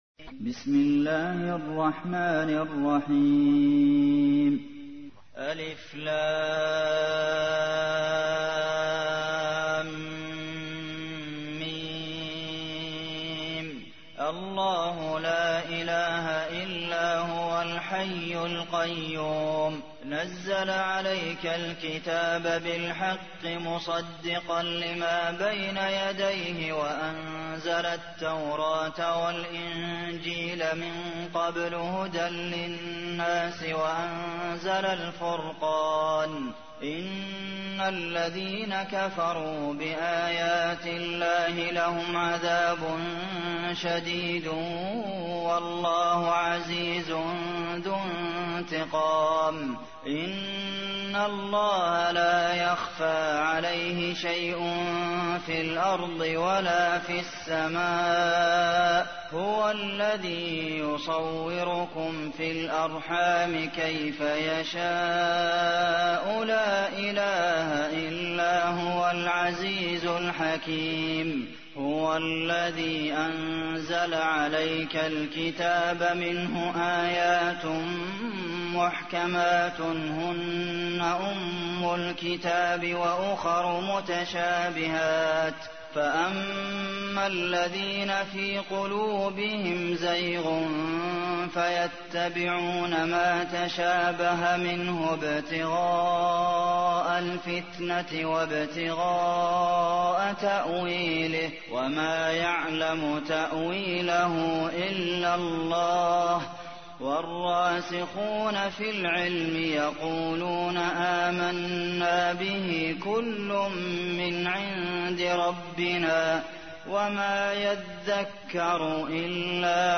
تحميل : 3. سورة آل عمران / القارئ عبد المحسن قاسم / القرآن الكريم / موقع يا حسين